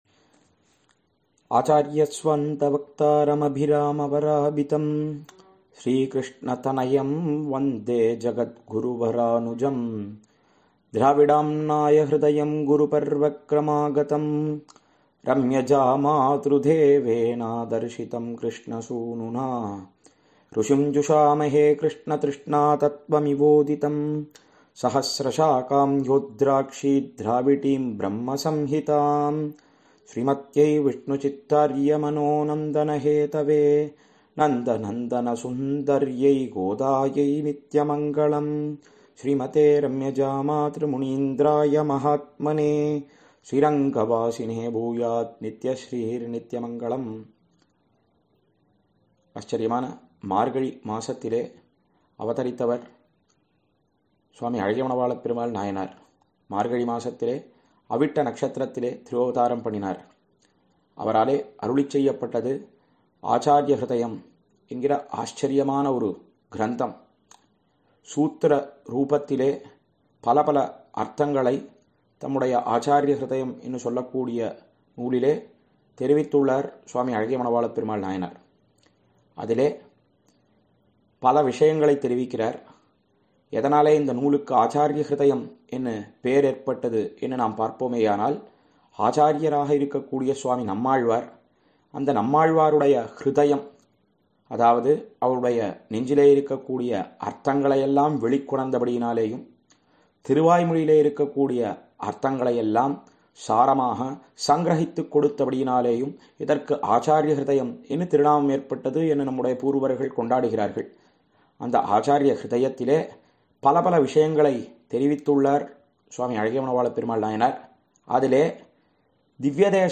சார்வரி ௵ மார்கழி ௴ மஹோத்ஸவ உபன்யாசம் –